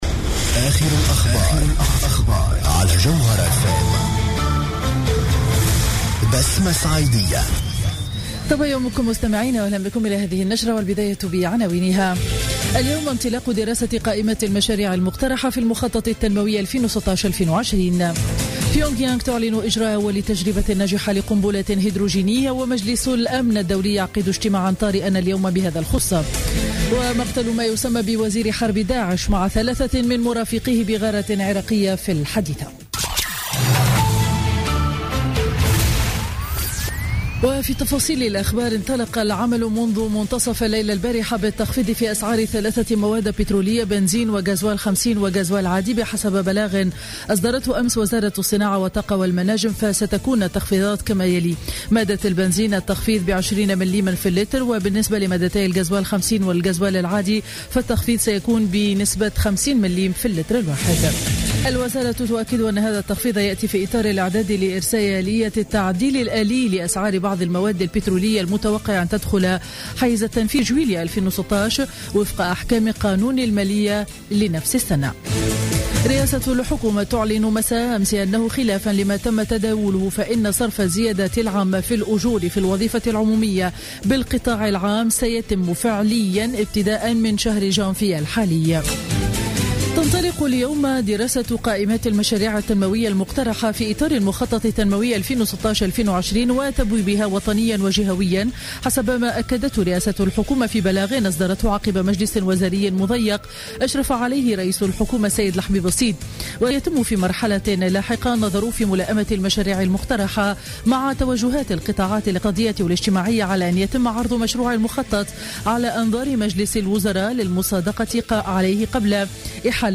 نشرة أخبار السابعة صباحا ليوم الأربعاء 6 جانفي 2016